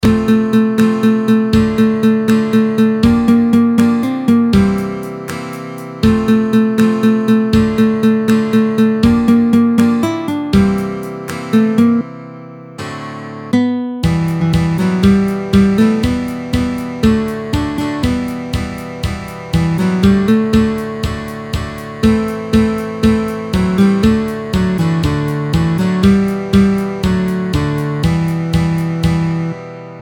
ternaire, binaire